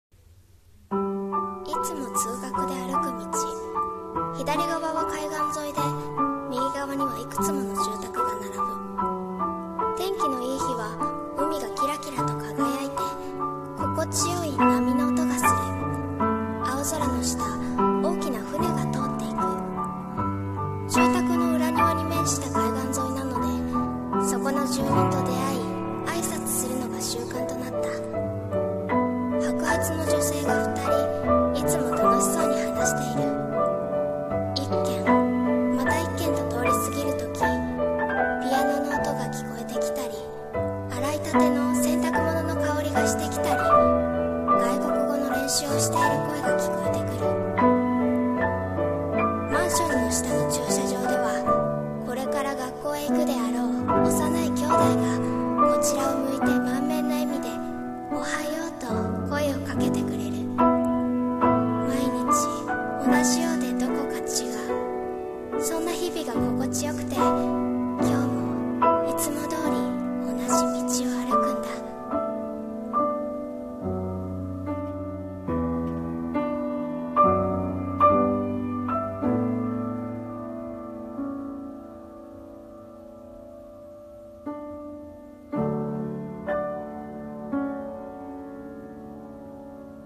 【声劇】あたたかな日常【台本】